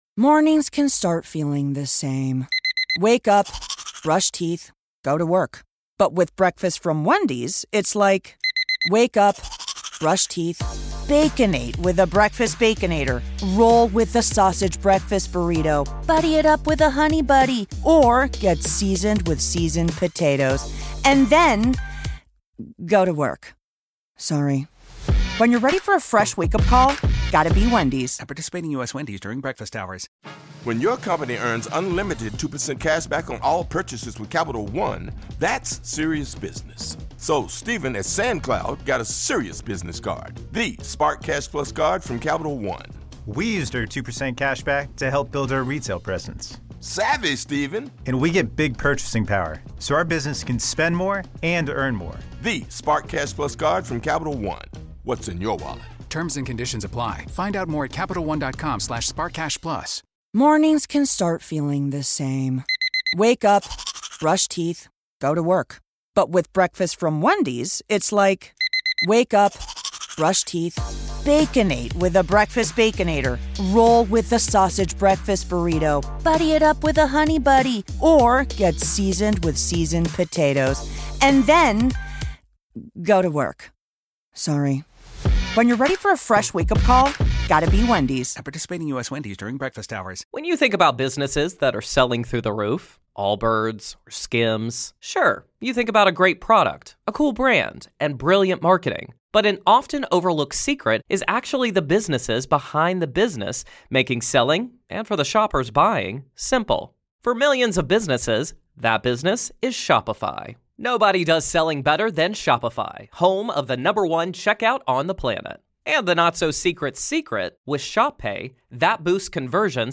a conversation about the Haunted Rock Island Roadhouse